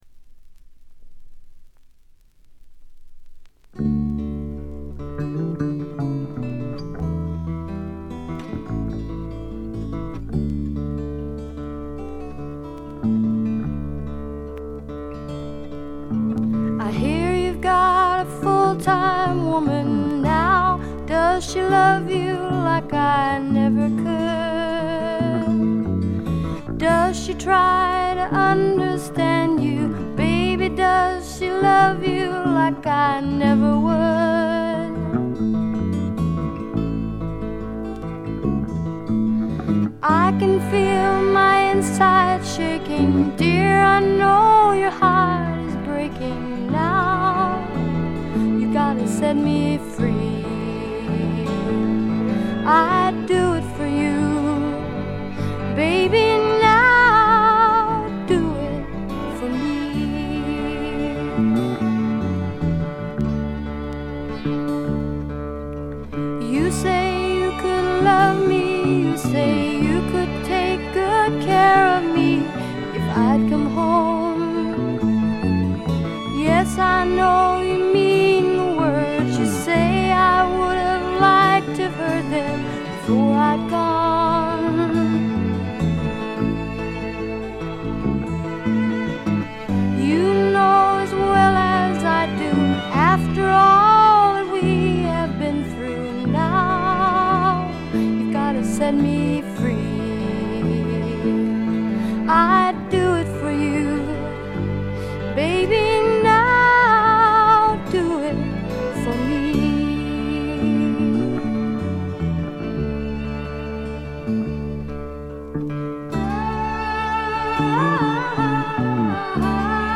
女性シンガーソングライター
バックがしっかり付いた重厚な音作りで、フォーキーな曲、アーシーな曲からハードな曲まで一気に聴かせます。
試聴曲は現品からの取り込み音源です。
Acoustic Guitar